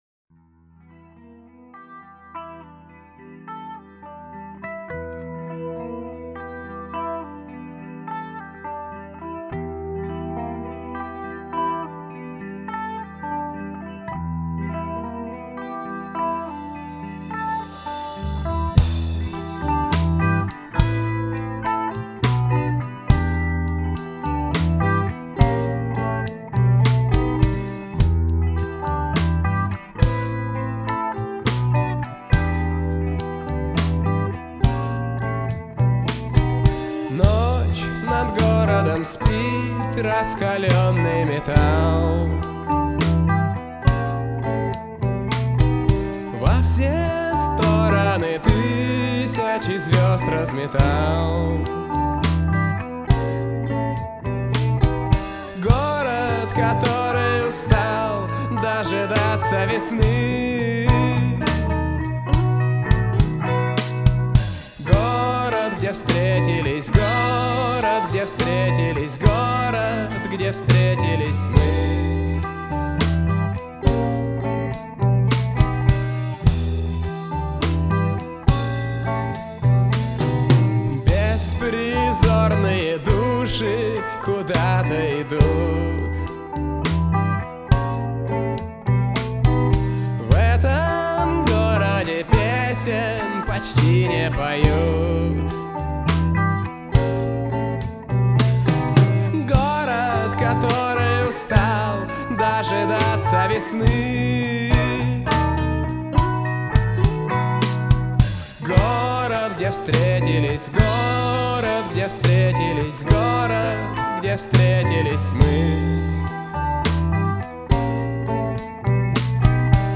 соло-гитара, ритм-гитара